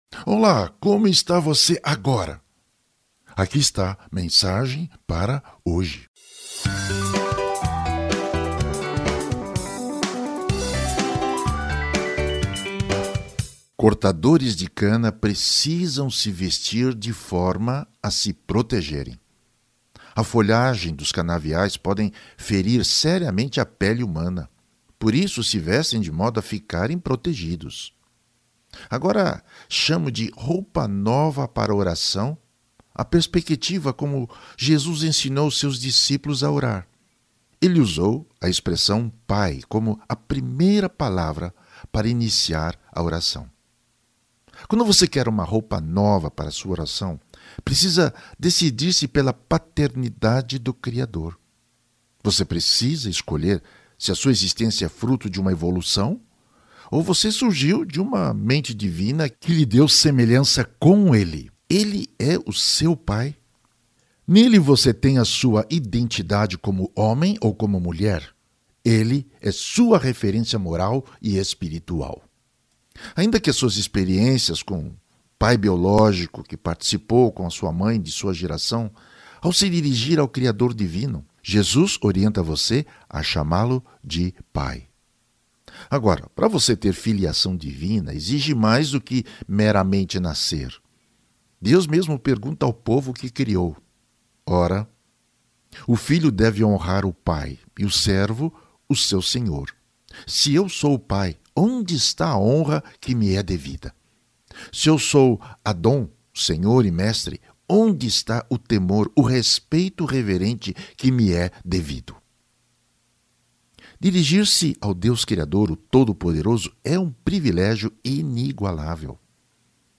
Clique aqui e ouça o áudio do texto abaixo com as anotações de rodapé dos textos bíblicos e informações adicionais.